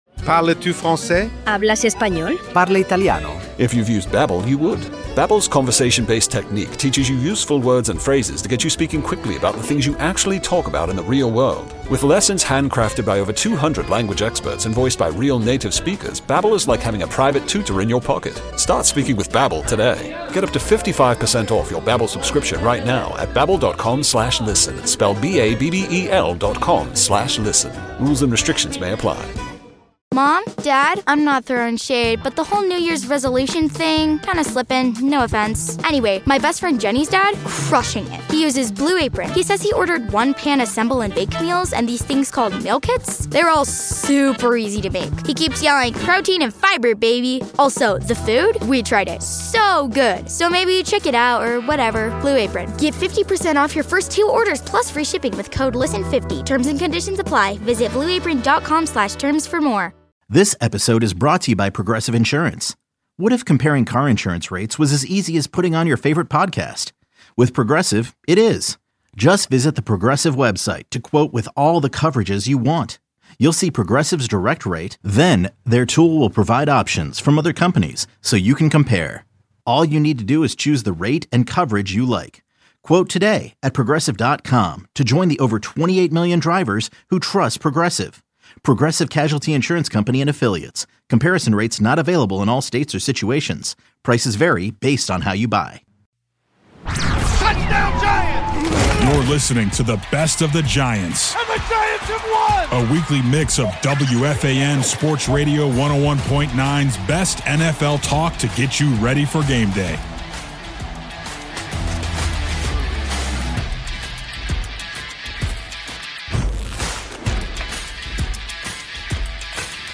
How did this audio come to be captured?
A weekly mix of WFAN Sports Radio 101.9's best NFL talk to get you ready for game day.